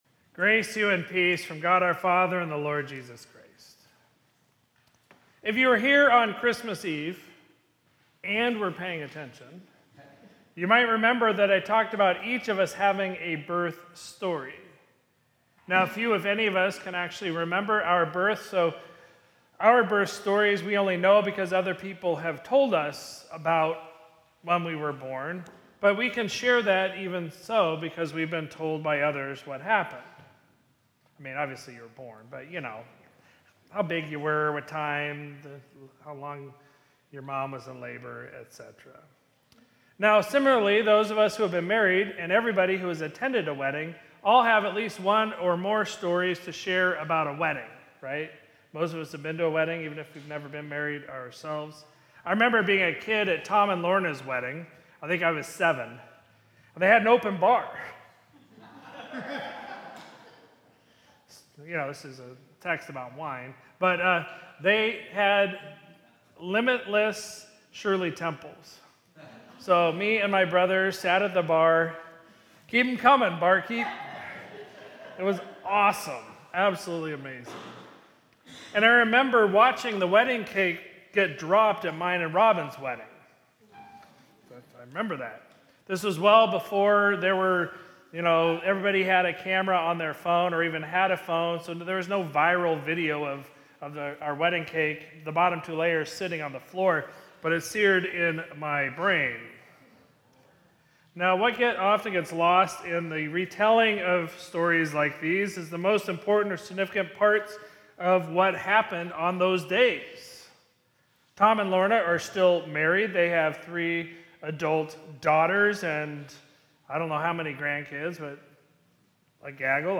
Sermon from Sunday, January 11, 2026